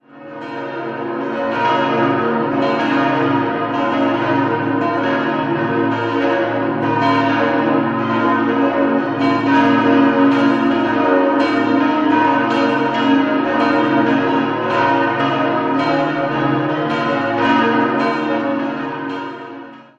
Die heutige Kirche geht auf Um- und Erweiterungsbauten im Jahr 1708 zurück. 6-stimmiges Geläut: b°-des'-es'-f'-as'-b' Die Glocken wurden 1933 bei der Gießerei Rüetschi in Aarau gefertigt.